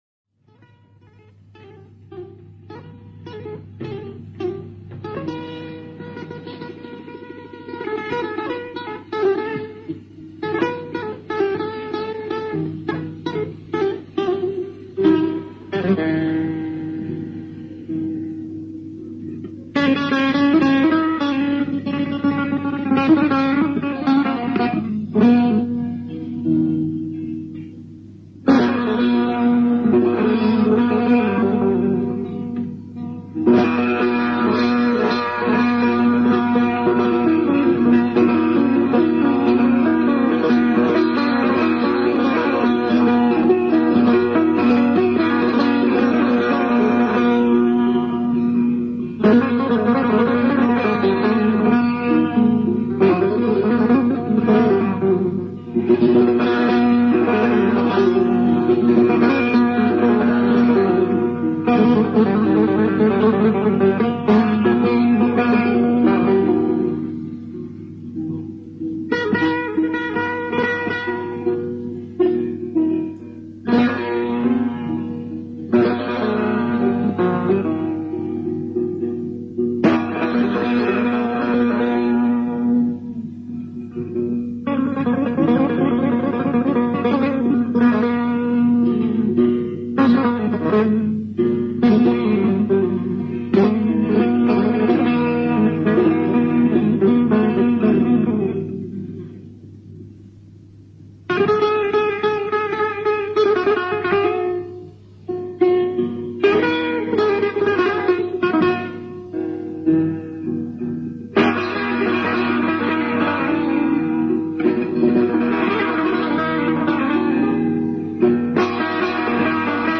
سه تار
آواز بیات ترک